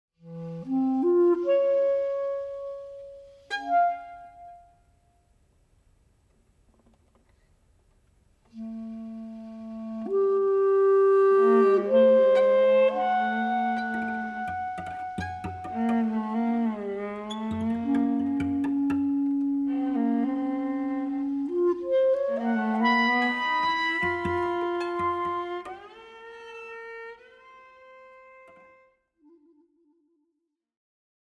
at bernard haitink hall of the conservatory of amsterdam
clarinet and shakuhachi
cello